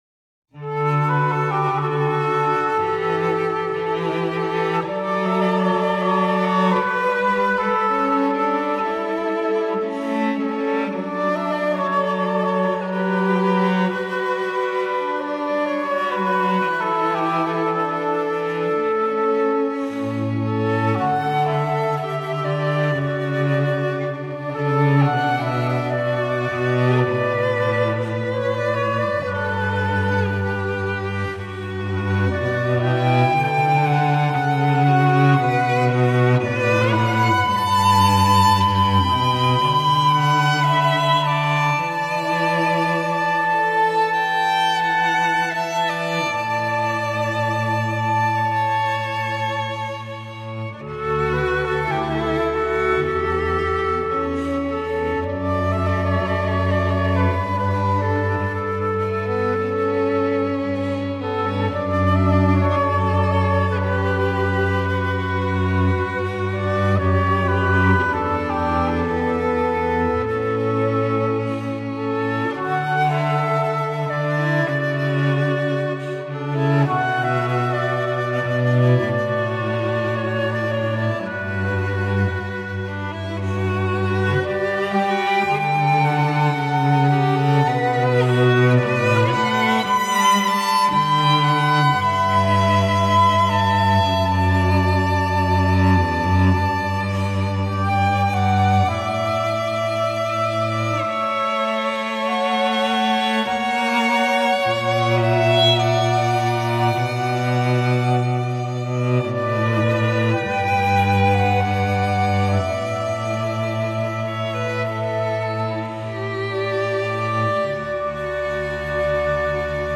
Cello, Violin, Flute